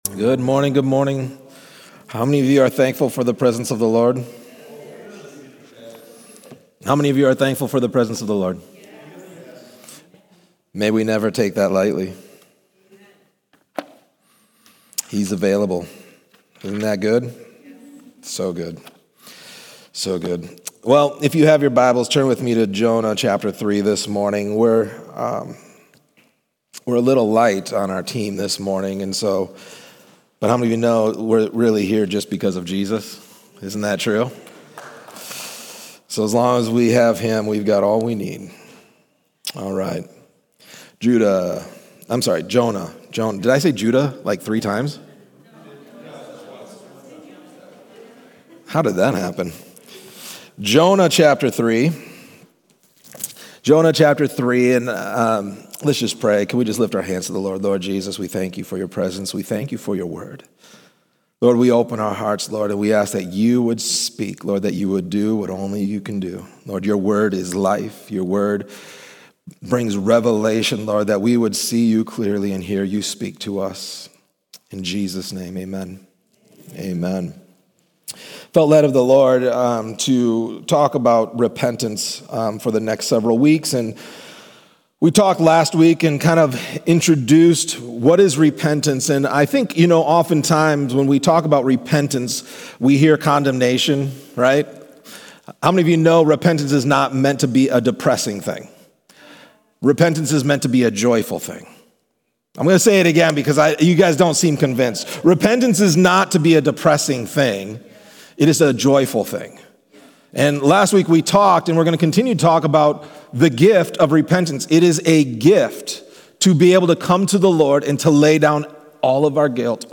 A message from the series "Single."